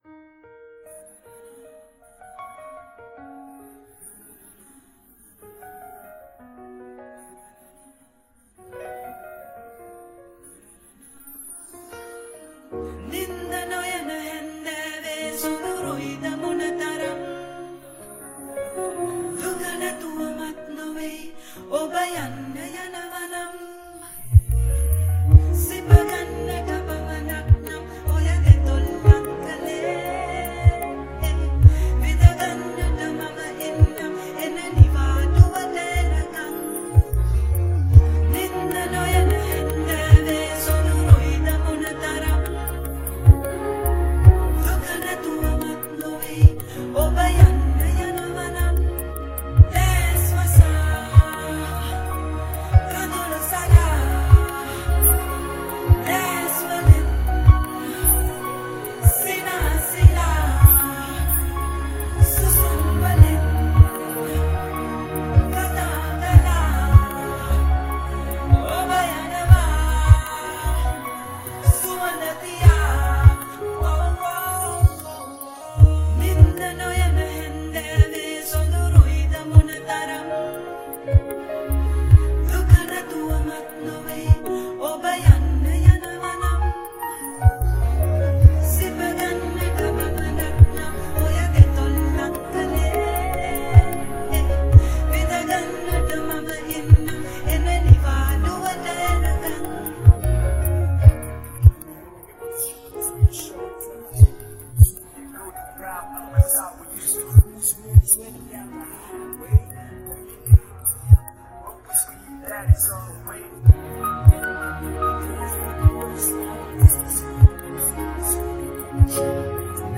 Play Karaoke & Sing with Us